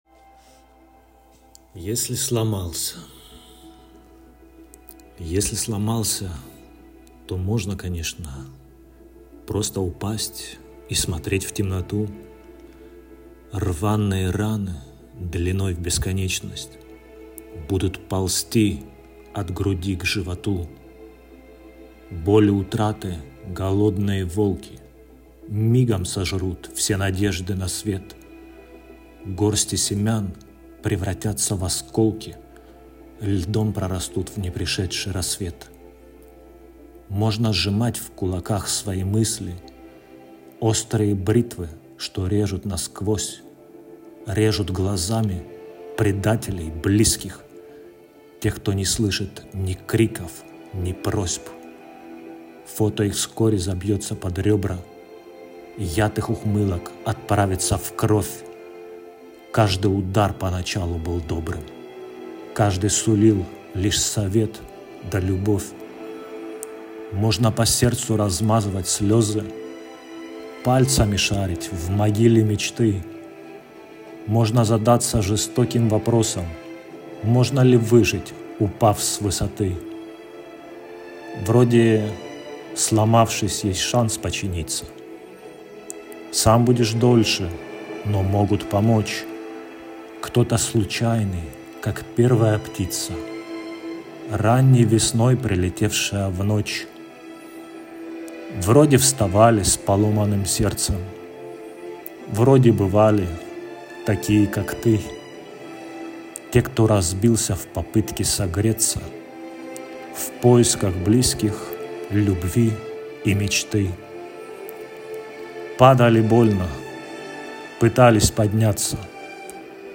Приятный тембр, хорошая дикция и вполне нормальная речь